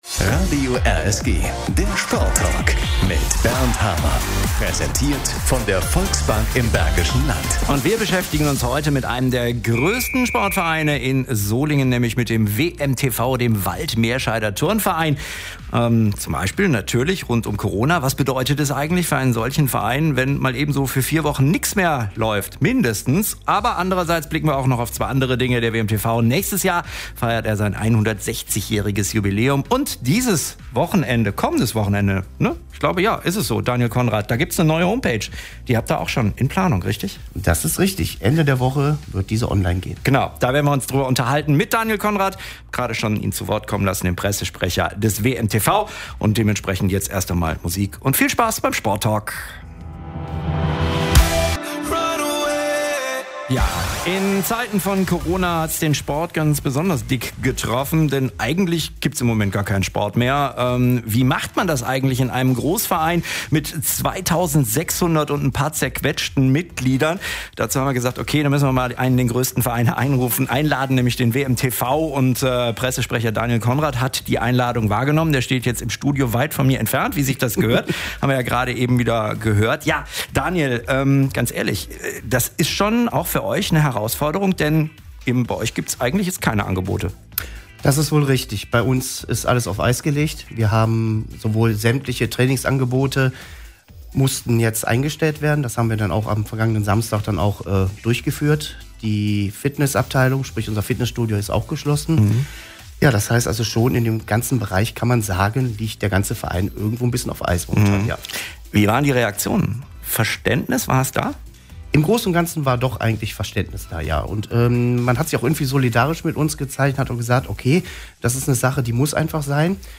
RSG-Sporttalk